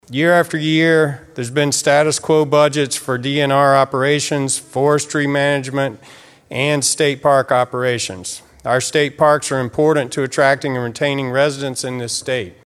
Senator Eric Giddens, a Democrat from Cedar Falls, says the bill falls short in other areas.